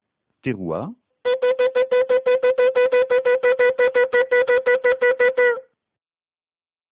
A chaque récitation du Hallel, on sonne trois sonneries du Choffar: une Tekiyah, une Terouah, et une Tekiyah.
Terouah)